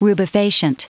Prononciation du mot rubefacient en anglais (fichier audio)
Prononciation du mot : rubefacient